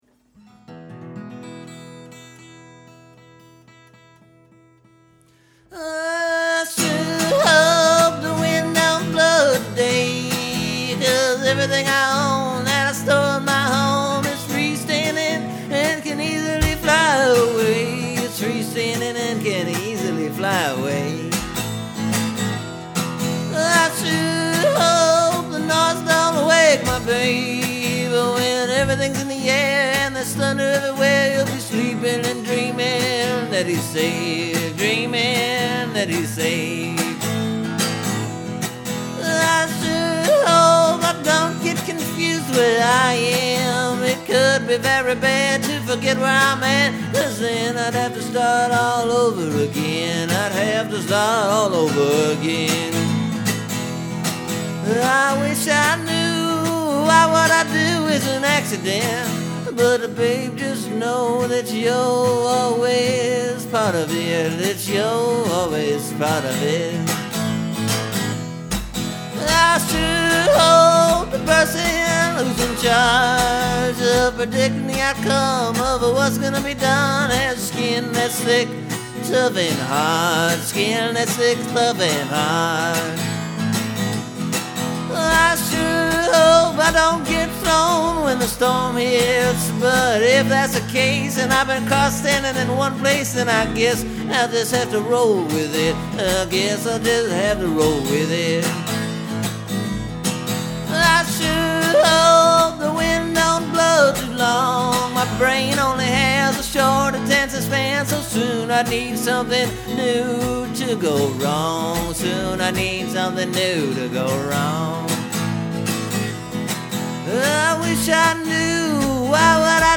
It’s a tad more polished, or something.
For this new recording I added a chorus.
And it’s just a straight up blues number.